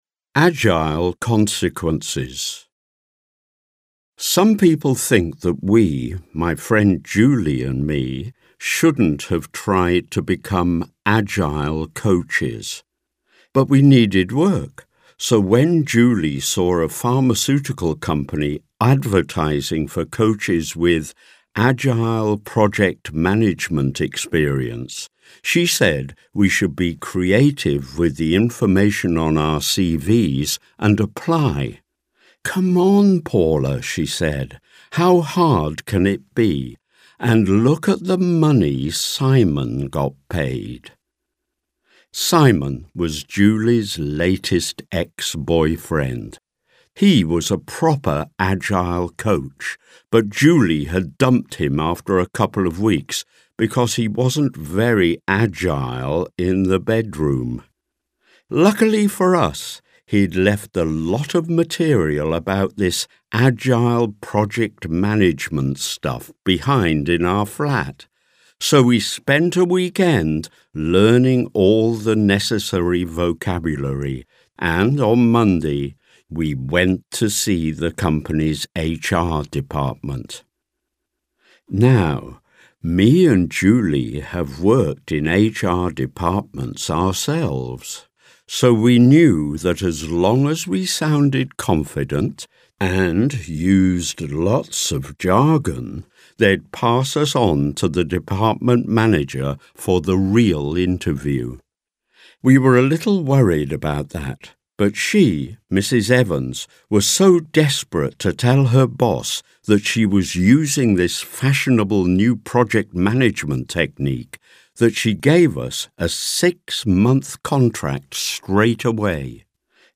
Brand business-spotlight Position 33 Sprachlevel mittel Audio-Übung Nein Original-Rubrik Short Story Lernsprache Englisch Mono-Lingual Mono-Lingual Dauer / Länge 616 Quelle Audio-Trainer Ausgabentitel Special: Bosses.